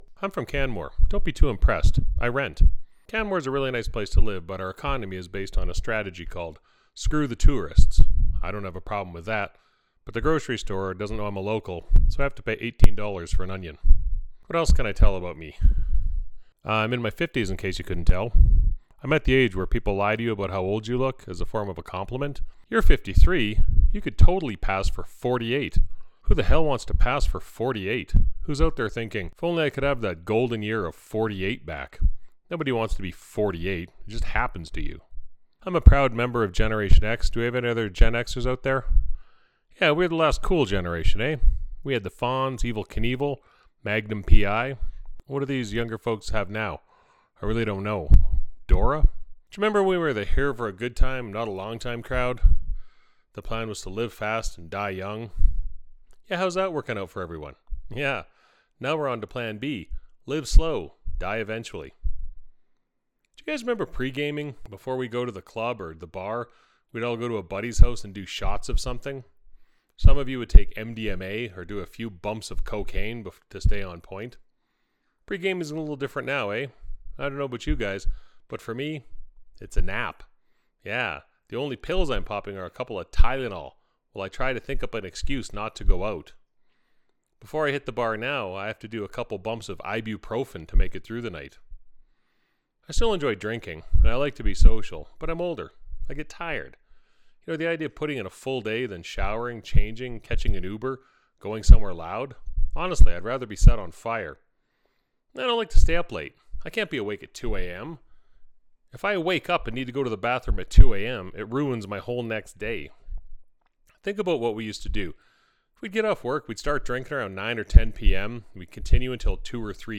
I take a long time to actually tell a joke onstage.